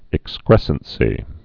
(ĭk-skrĕsən-sē)